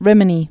(rĭmə-nē, rēmē-)